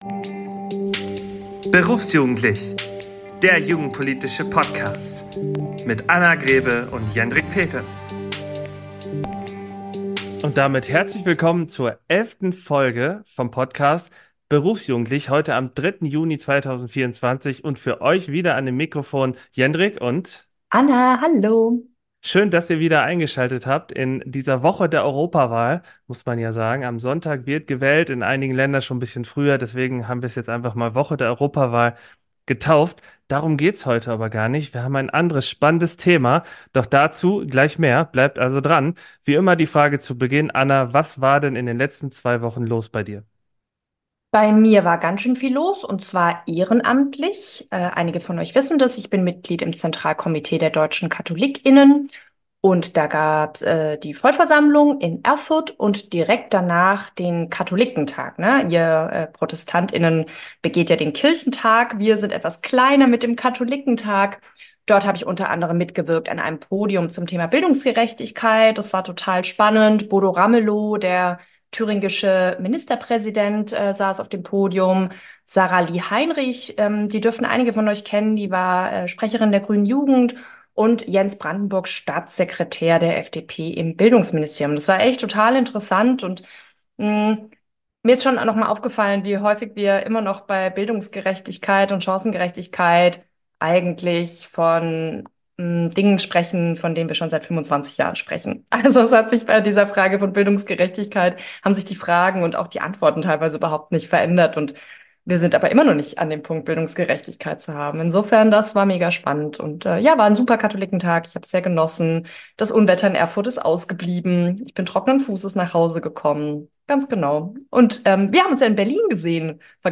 Sie diskutieren aktuelle Herausforderungen, alternative Modelle und lassen Raum zur eigenen Interpretation. Dabei kommen auch viele Hörer:innen zu Wort, die sich per Sprachnachricht oder schriftlich über die Sozialen Medien gemeldet haben.